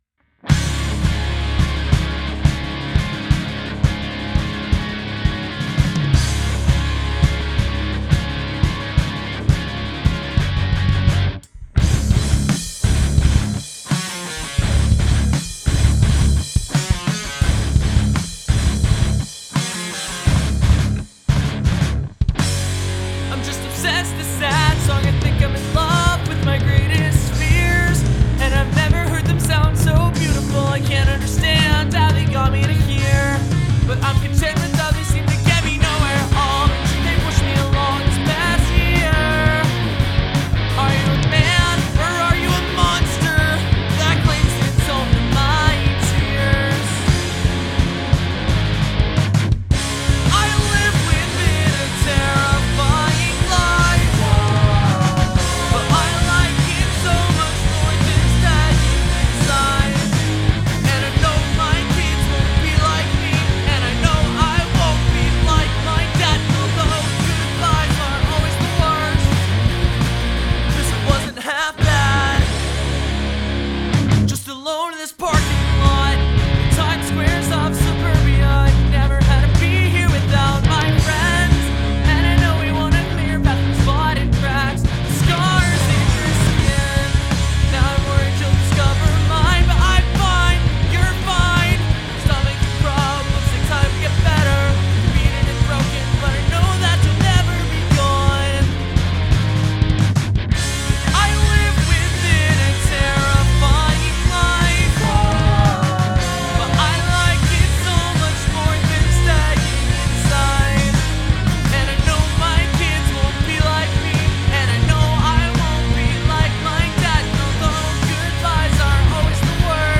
My first song I have ever done with a full band!
Regarding the drums first: The snare sounds very dull to me, and I don't really like the cymbal being panned front and center (like it thinks it's the star of the band, instead of the singer! Huh!) The panning of the toms were a bit distracting for my taste, they were hard panned both Left and Right, like you placed a tom on each side of the stage. I found the lead vocal to be a bit too dry, and the background vocals too wet - maybe put them all in same room with a moderate reverb setting. The song has good energy and performances, just needs a few mixing tweaks.